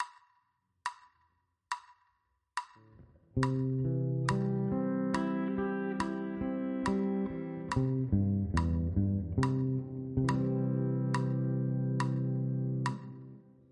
Ex 2 – C-Dur Arpeggio – A-Shape